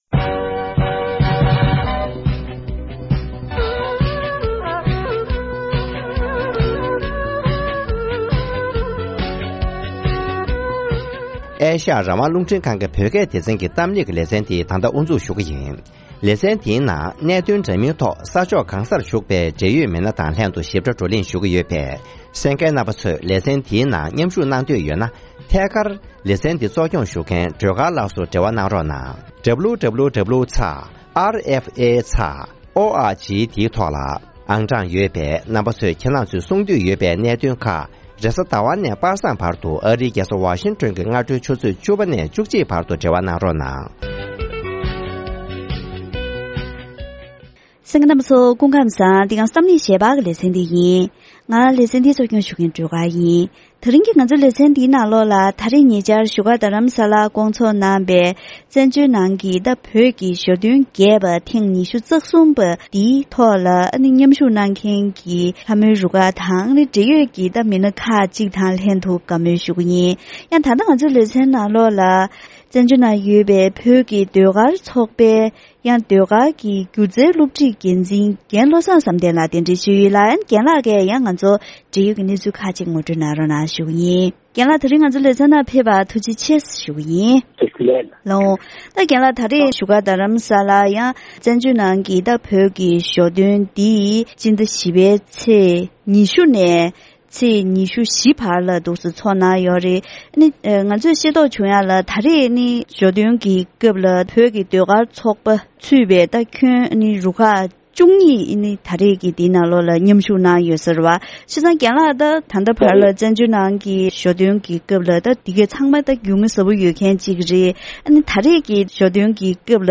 བཙན་བྱོལ་ནང་བོད་ཀྱི་ལྷ་མོའི་འཁྲབ་གཞུང་ལ་གཅེས་སྐྱོང་གནང་བཞིན་པའི་སྐོར་སྒྱུ་རྩལ་དགེ་རྒན་དང་ལྷ་མོའི་དགེ་རྒན་ཁག་དང་བཀའ་མོལ་ཞུས་པ།